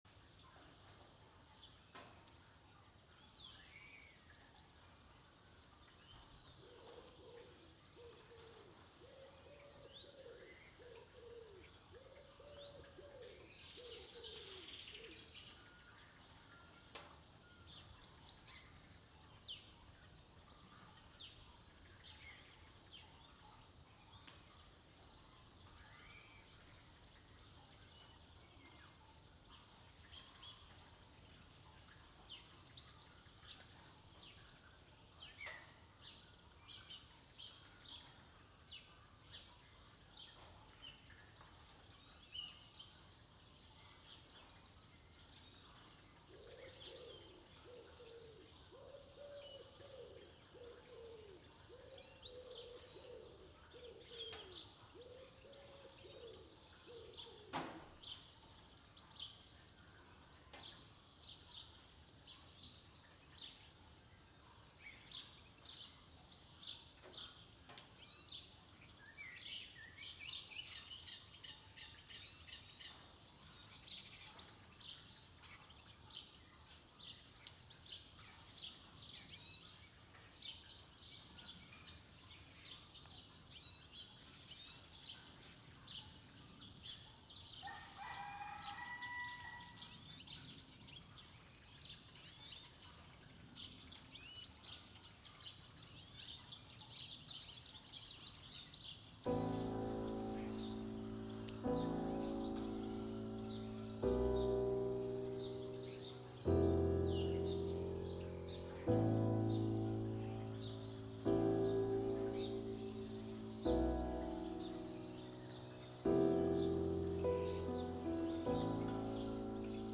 Dorfmorgen Ein Sonntag auf dem Dorf im Februar 2023. 5 Grad über Null. Einsetzender Regen, ein Schwarm Stiglitze, die Futter suchen und bekommen, Gottesdienst, ein Mann geht mit dem Hund durchs Dorf – ein Hund schlägt an. Recorder und Microfone werden nass.
Location: ♥ Diese Collage besteht aus Teilen von insgesamt 60 Minuten Aufnahme, die ich auch hätte unbearbeitet bringen können.
dorfmorgen.mp3